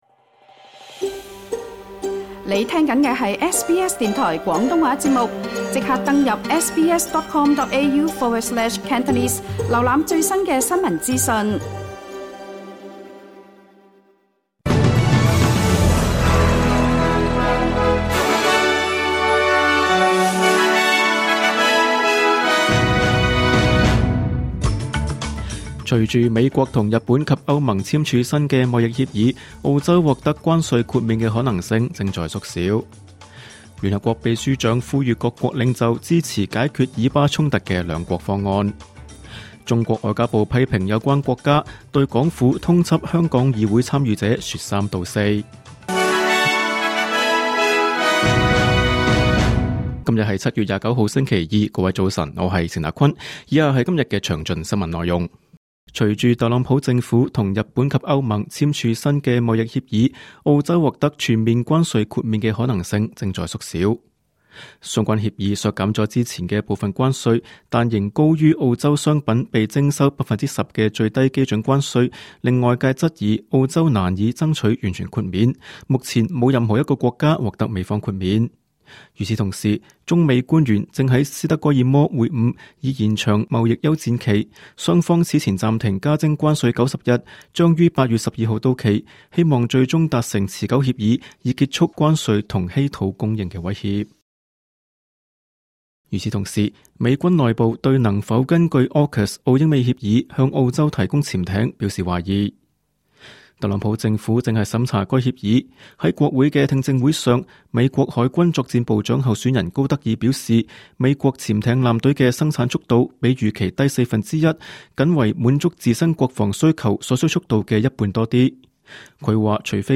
2025 年 7 月 28 日 SBS 廣東話節目詳盡早晨新聞報道。